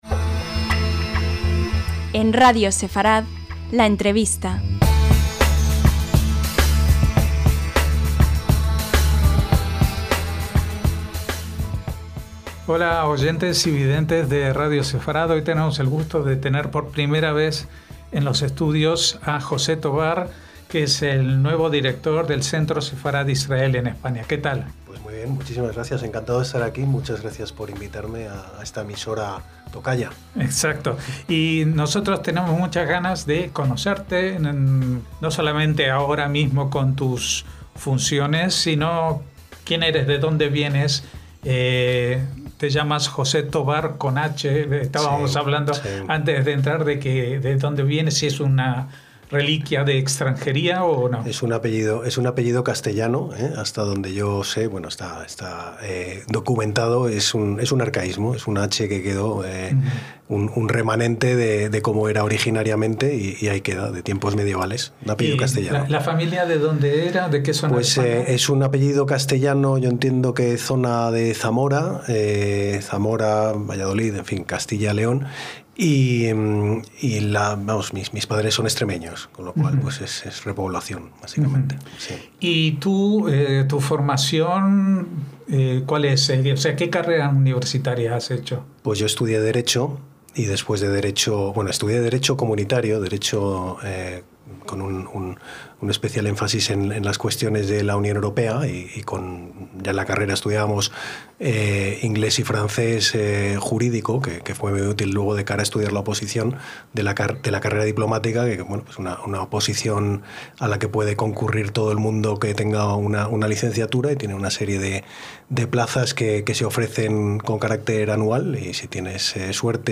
LA ENTREVISTA - José Thovar Lozano (Madrid, 1975) es el nuevo director general del Centro Sefarad-Israel, cargo que asumió en julio de este 2025, en sustitución de Jaime Moreno Bau.